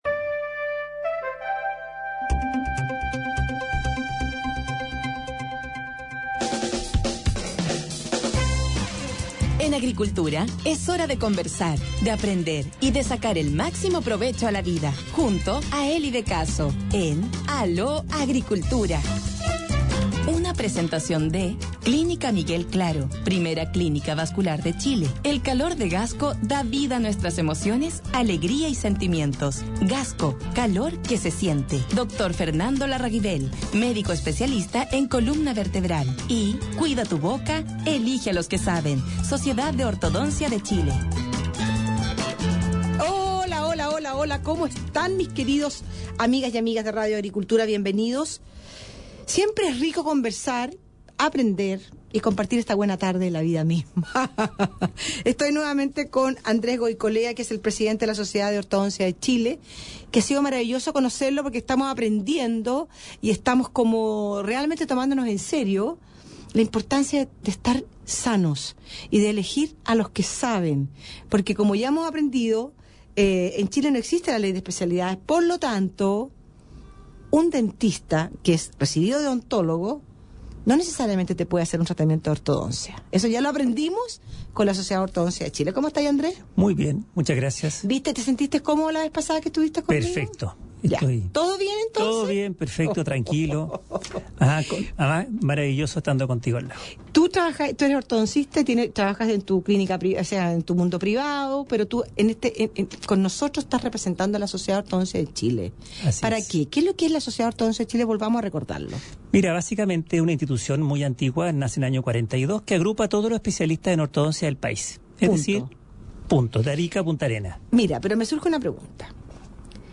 Sortchile | Entrevista en Radio Agricultura 21 de Julio de 2016